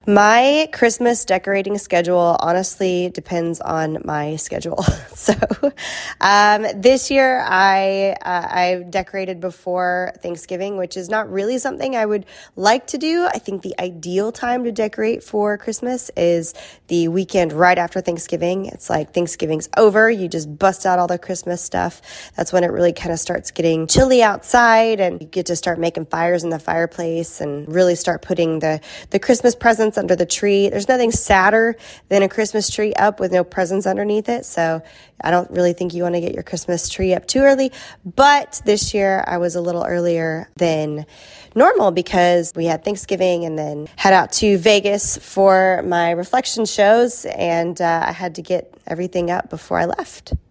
So with that in mind, her family is already enjoying a Christmas tree. Carrie explains: